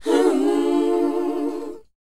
WHOA C D.wav